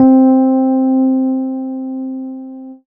Rhodes_C3.wav